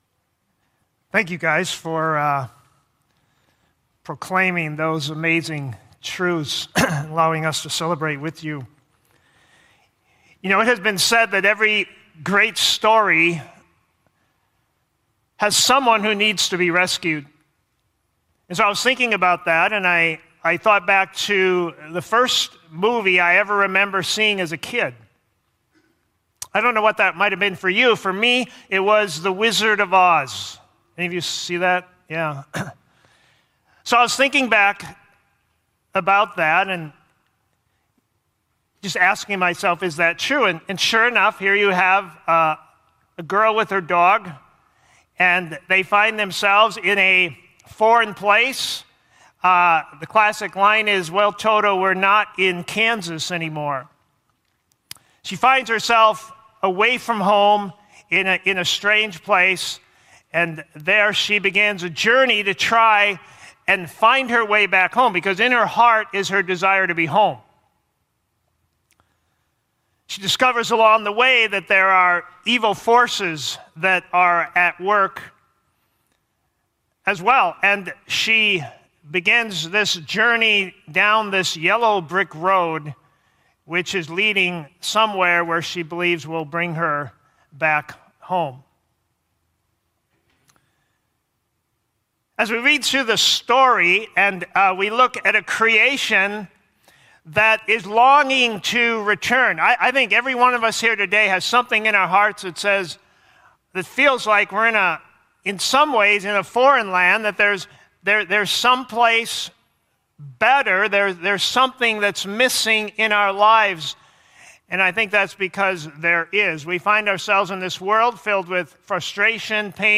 First-Baptist-Sermon-October-4-2020.mp3